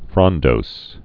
(frŏndōs)